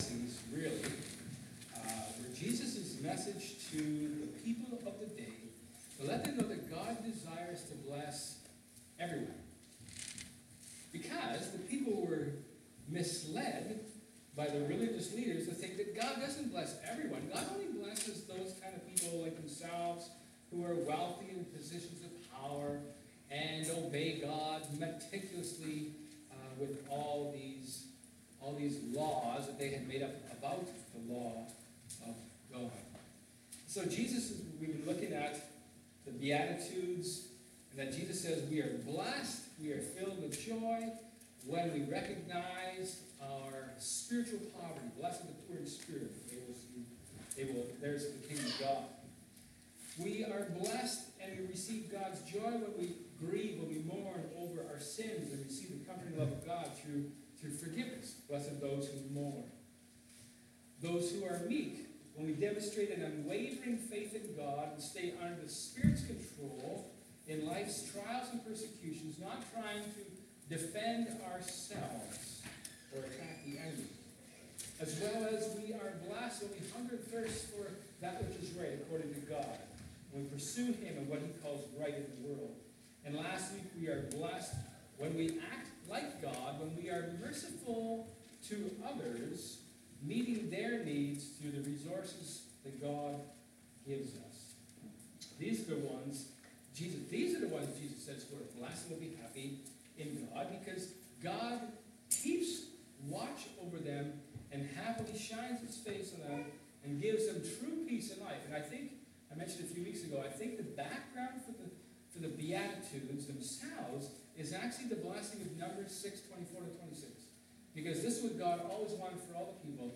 Matthew 5:8 Service Type: Sermon